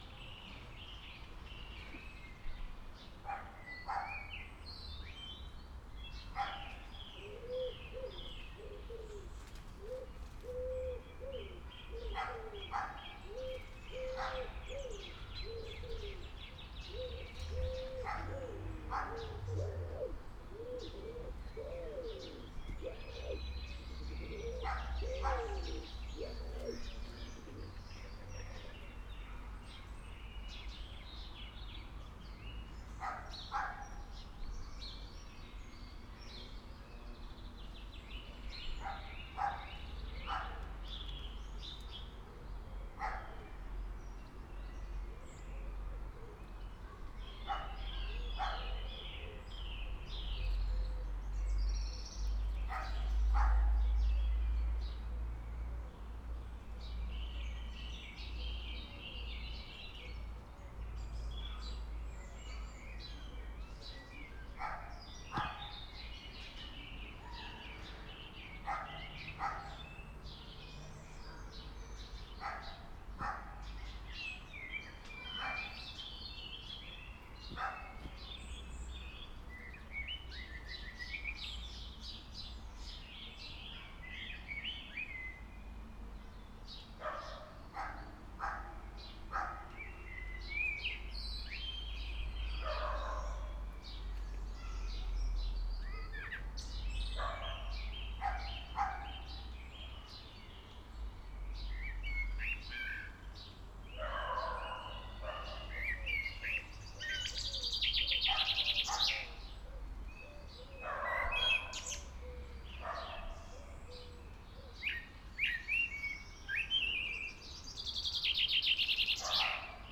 Barking dogs an birds, Auray.
• 1x Schoeps MSTC 64 U microphone (ORTF) + 2x Cinela Leo 20 + fur
• 1x Neumann KU 100 - Dummy Head (Binaural)
dogsandbirds.mp3